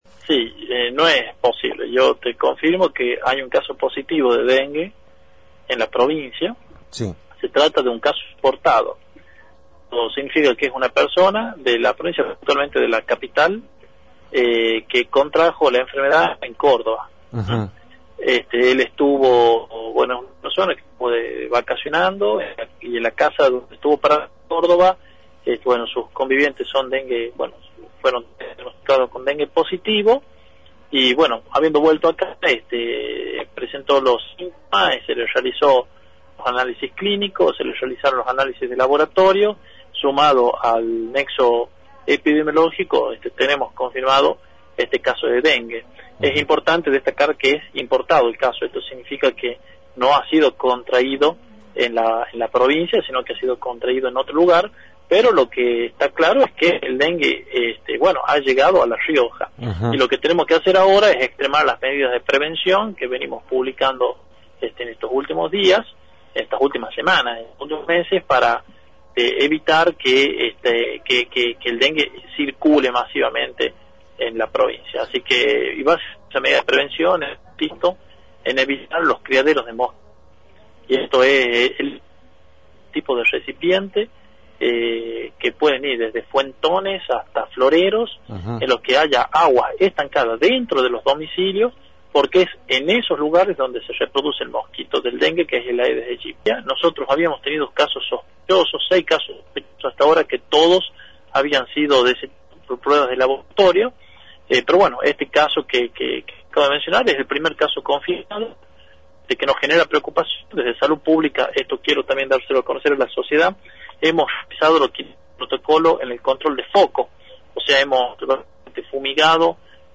Juan una Corzo, ministro de Salud, por Radio Independiente